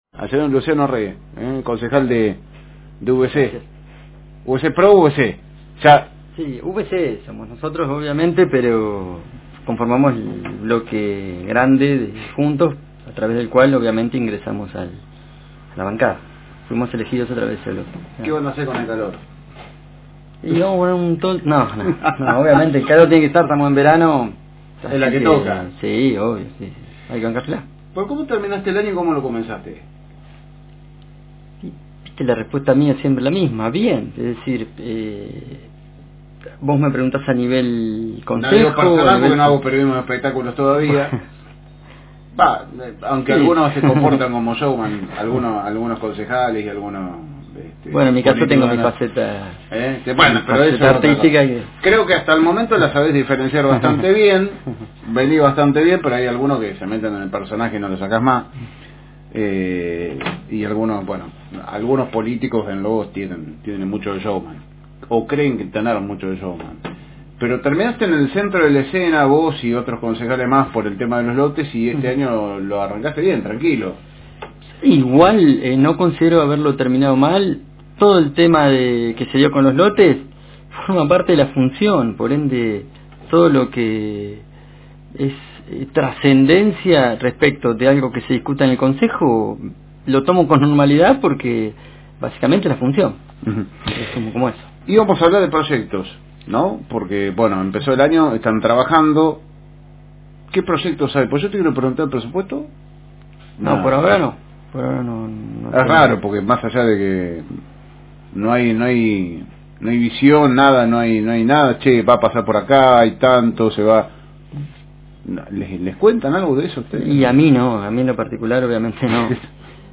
Paso por los estudios de Ameghino 366